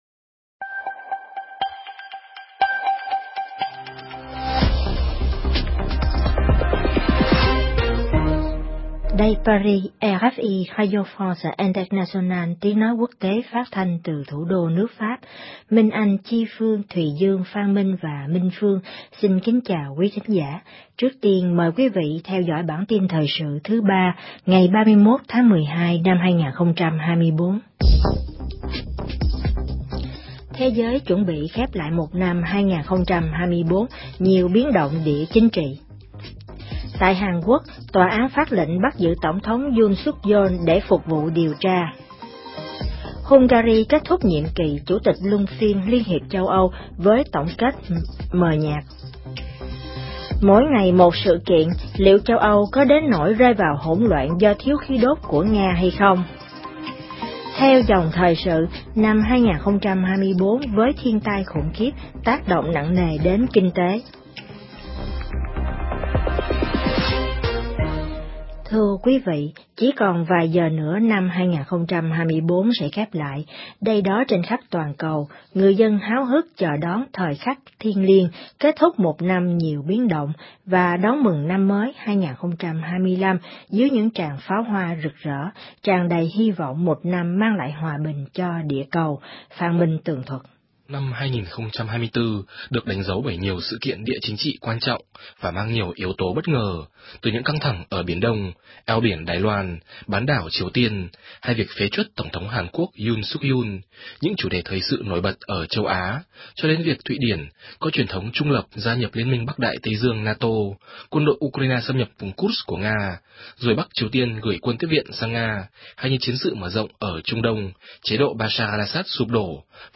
CHƯƠNG TRÌNH PHÁT THANH 60 PHÚT Xem tin trên website RFI Tiếng Việt Hoặc bấm vào đây để xem qua Facebook